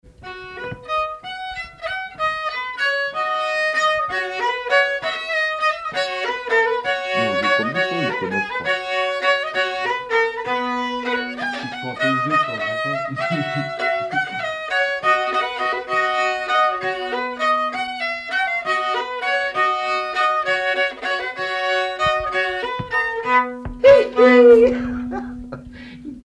danse : valse
circonstance : bal, dancerie
Pièce musicale inédite